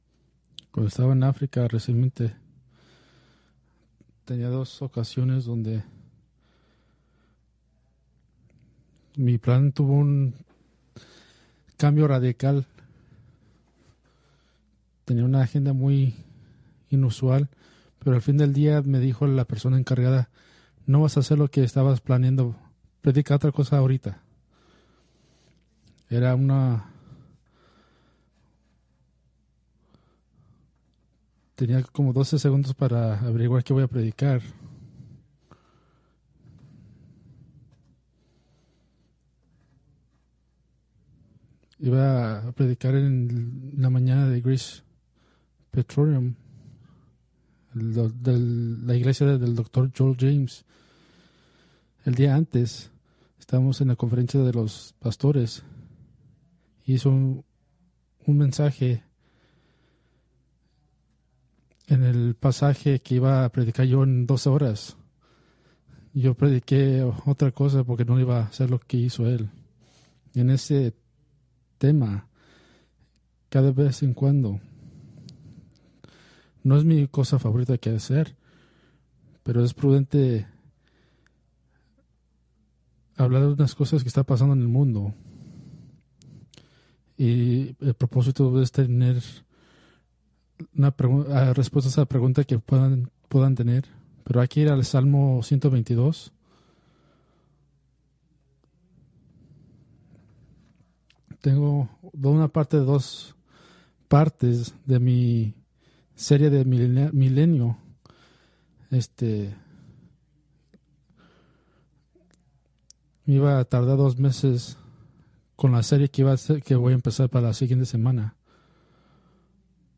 Preached June 22, 2025 from Escrituras seleccionadas